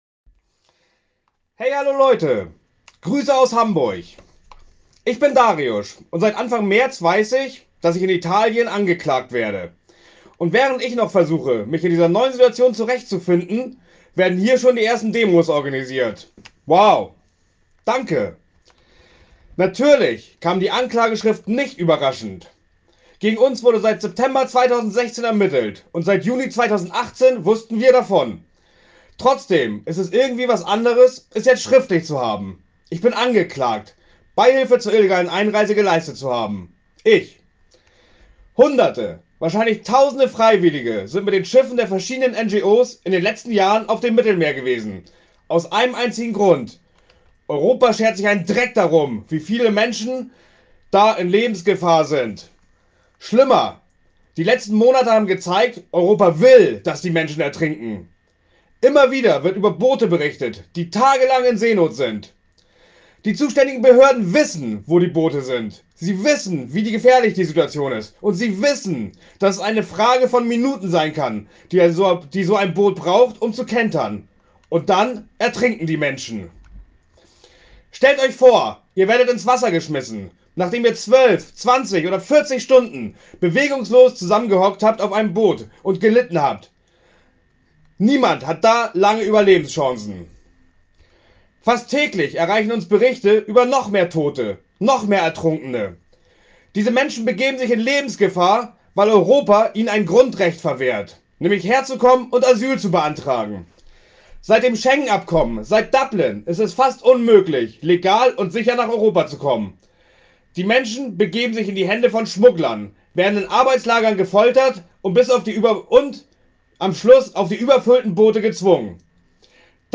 Die Bochumer Seebrücke hat am Samstag mit einer Wandermahnwache erneut daran erinnert, dass angesichts der leer stehenden Flüchtlingsunterkünfte genügend Platz für viele Menschen in Bochum ist, die unter unwürdigsten Bedingungen an den Grenzen Europas leben. Amnesty International berichtete, wie systematisch Schiffsbesatzungen kriminalisiert werden, die Geflüchtete im Mittelmeer vor dem Ertrinken retten.